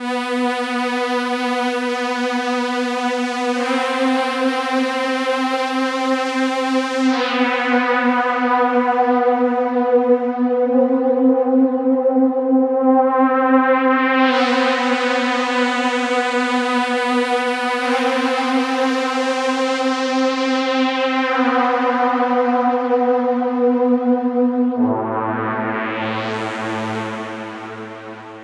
Session 04 - Trance Pad 02.wav